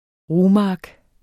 Udtale [ ˈʁuː- ]